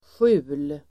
Ladda ner uttalet
Uttal: [sju:l]